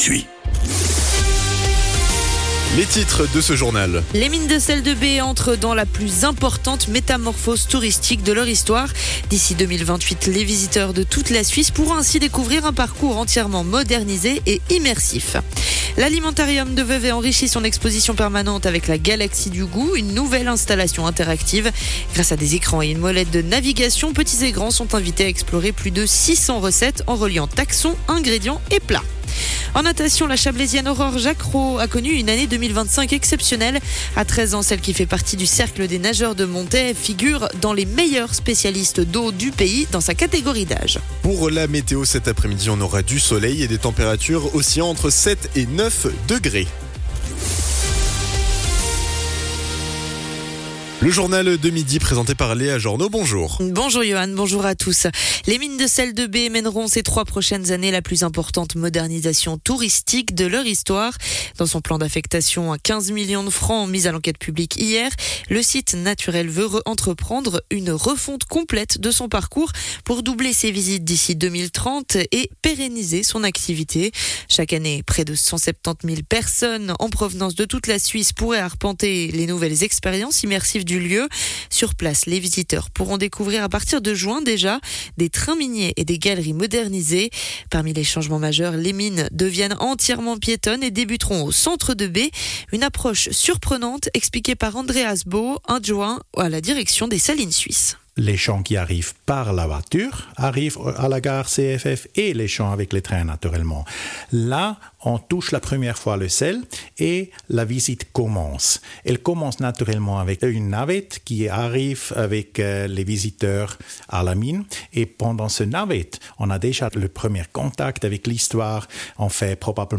Le journal de midi du 15.01.2026